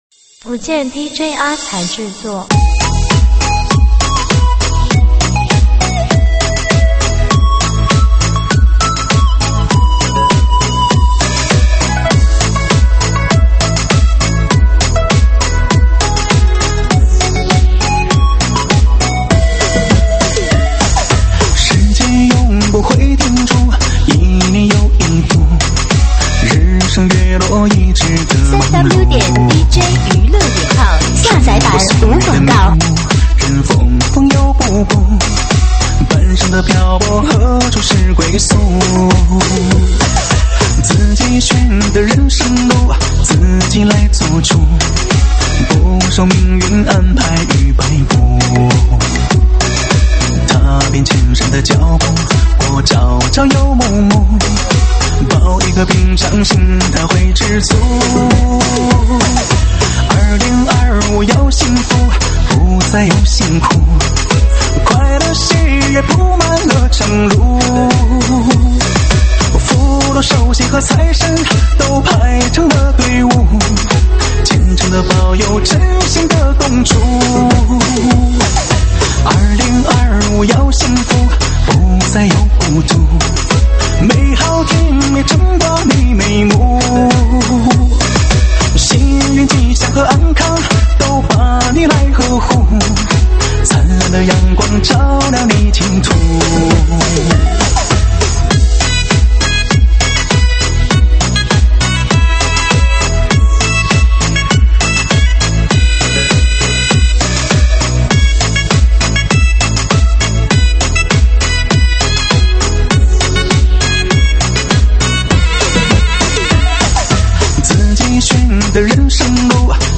舞曲类别：吉特巴